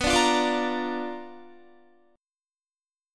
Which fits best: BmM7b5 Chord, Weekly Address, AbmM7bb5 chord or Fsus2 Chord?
BmM7b5 Chord